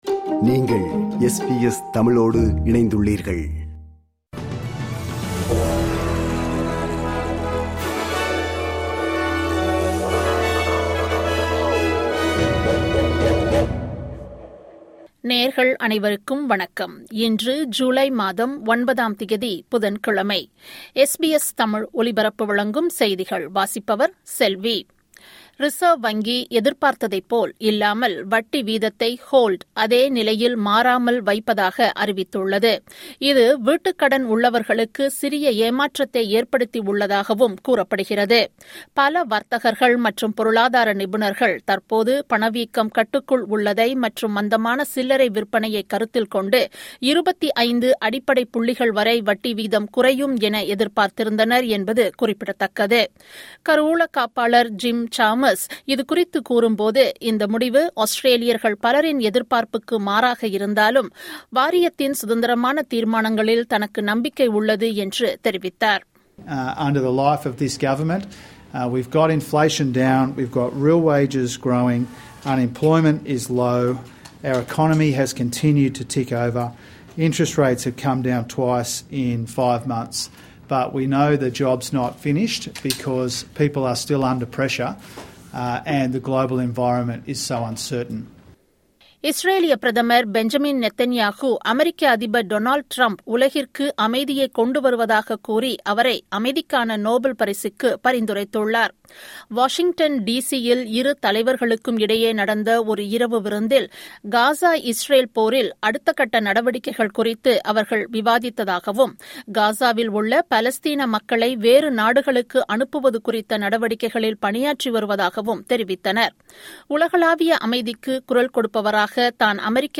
SBS தமிழ் ஒலிபரப்பின் இன்றைய (புதன்கிழமை 09/07/2025) செய்திகள்.